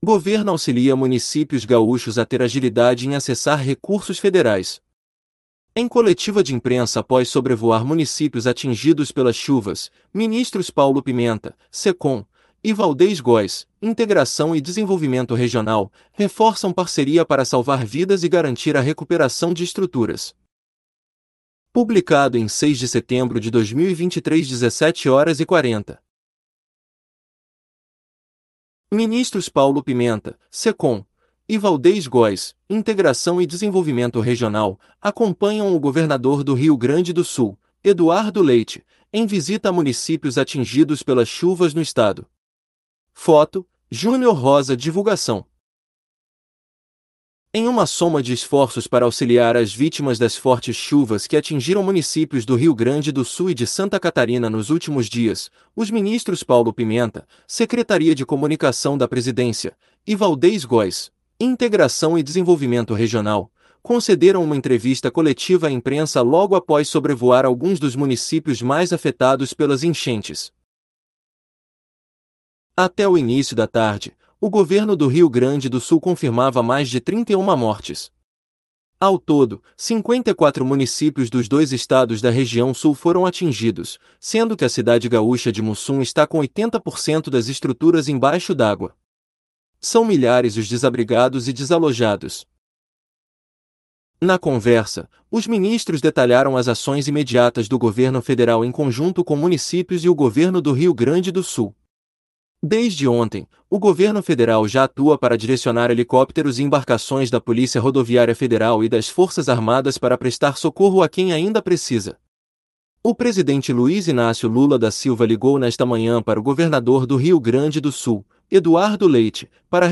Em coletiva de imprensa após sobrevoar municípios atingidos pelas chuvas, ministros Paulo Pimenta (Secom) e Waldez Góes (Integração e Desenvolvimento Regional) reforçam parceria para salvar vidas e garantir a recuperação de estruturas